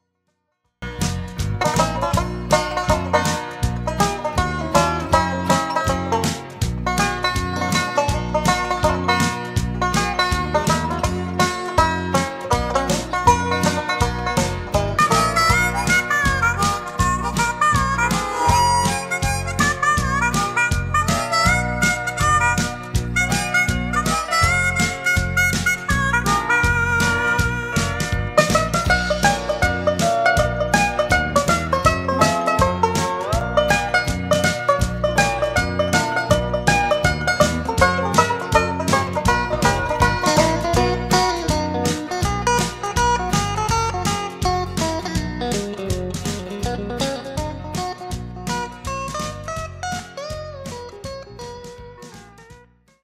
Traditional
Listen to Nashville Banjos perform "He's Got The Whole World" (mp3)